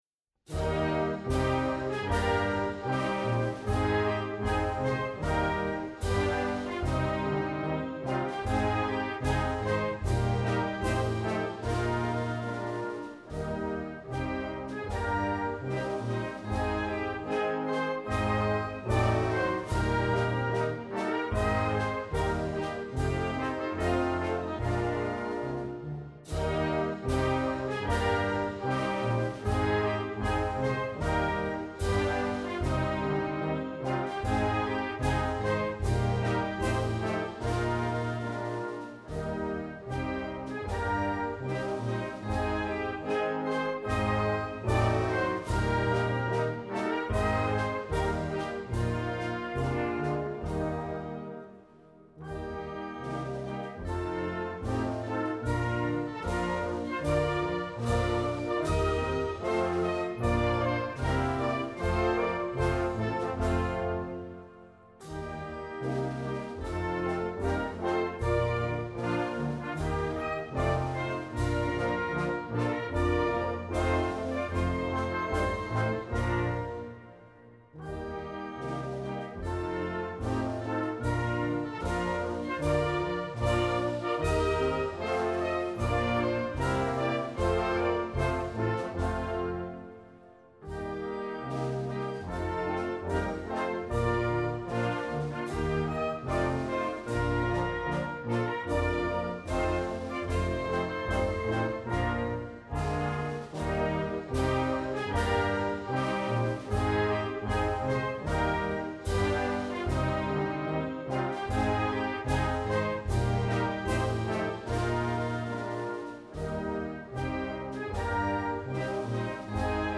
Gattung: Prozessionsmarsch
Besetzung: Blasorchester